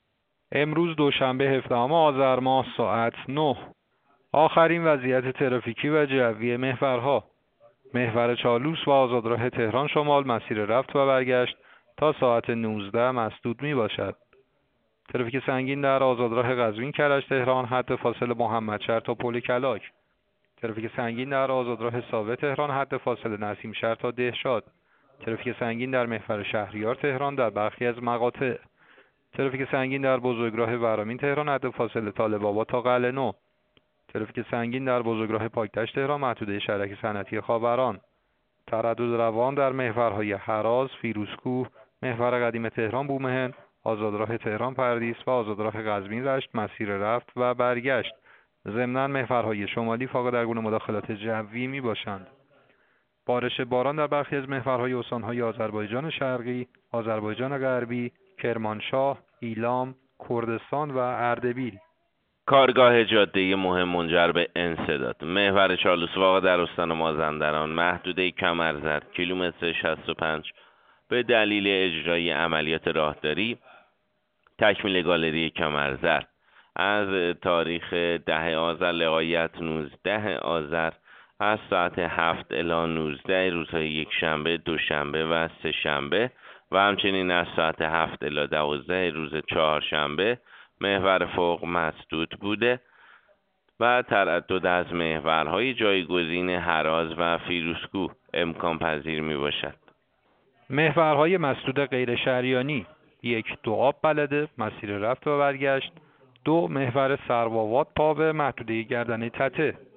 گزارش رادیو اینترنتی از آخرین وضعیت ترافیکی جاده‌ها ساعت ۹ هفدهم آذر؛